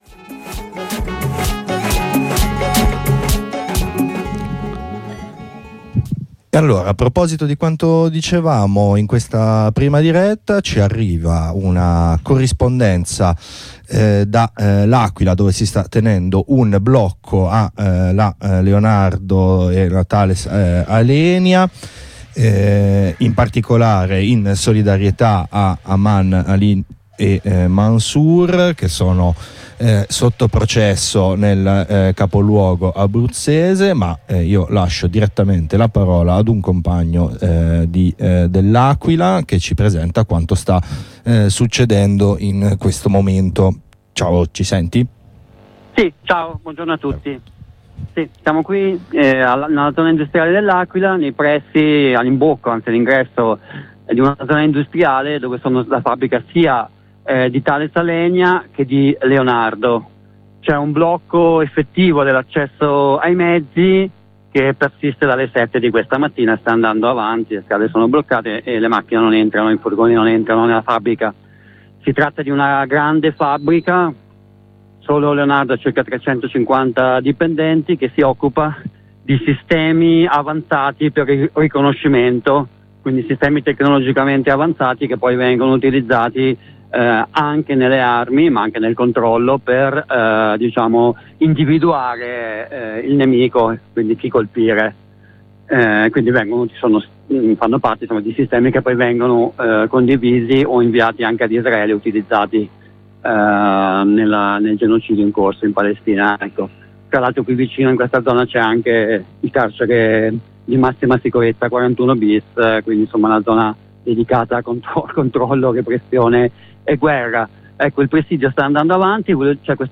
Nella giornata di oggi, 12 settembre, si è tenuto un blocco dello stabilimento della Leonardo e Tales Alenia presso L’Aquila. Ecco una corrispondenza da un compagno del posto: oltre alla necessità di inceppare e ostacolare la filiera produttiva delle armi